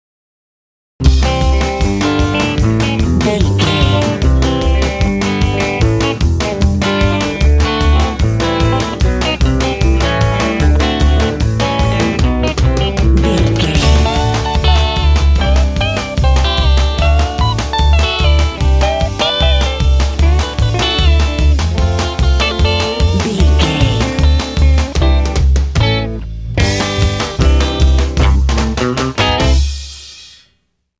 Ionian/Major
Fast
happy
playful
cheerful/happy
drums
bass guitar
acoustic guitar